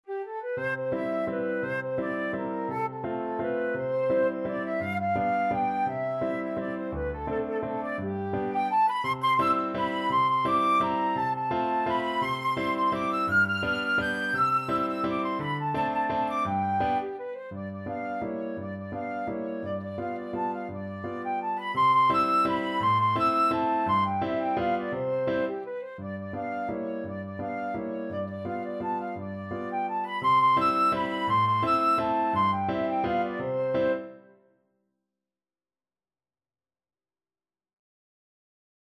Flute
3/4 (View more 3/4 Music)
C major (Sounding Pitch) (View more C major Music for Flute )
Classical (View more Classical Flute Music)